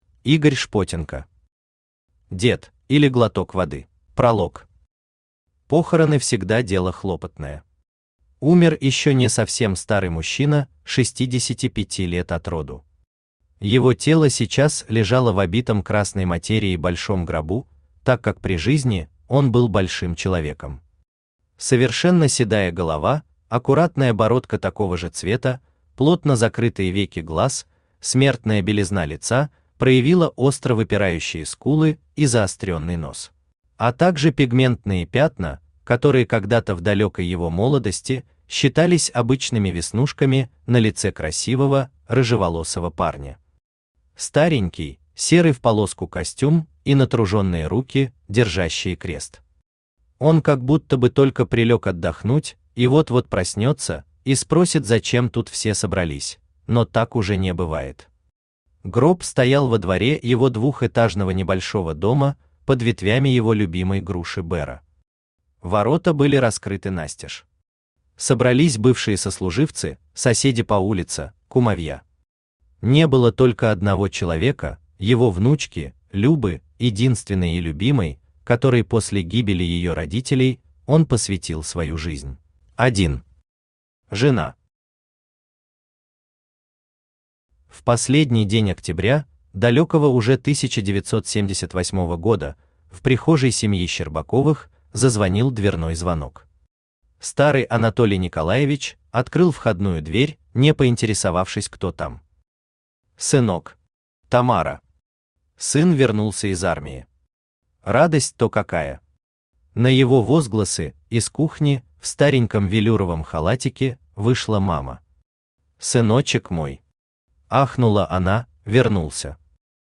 Аудиокнига Дед, или Глоток воды | Библиотека аудиокниг
Aудиокнига Дед, или Глоток воды Автор Игорь Анатольевич Шпотенко Читает аудиокнигу Авточтец ЛитРес.